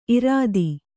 ♪ irādi